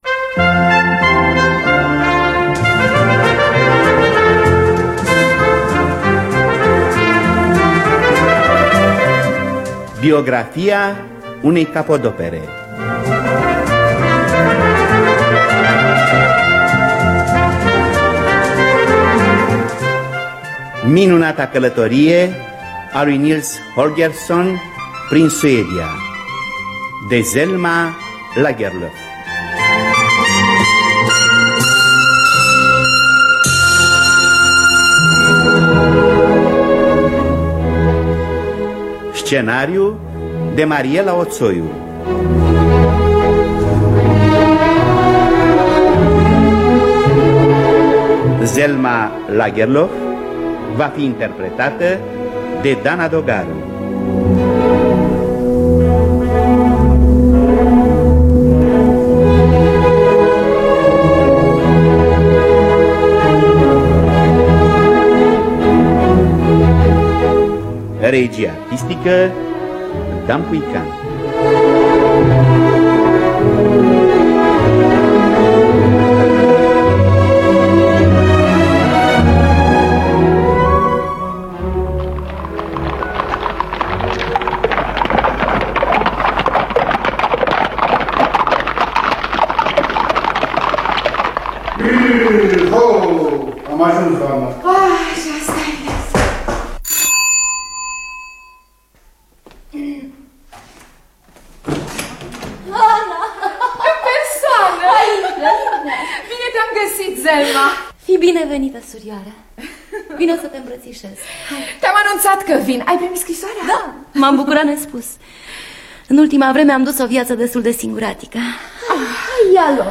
Biografii, Memorii: Selma Lagerlof – Minunata Calatorie A Lui Nils Holgersson In Suedia (1991) – Teatru Radiofonic Online
Scenariu radiofonic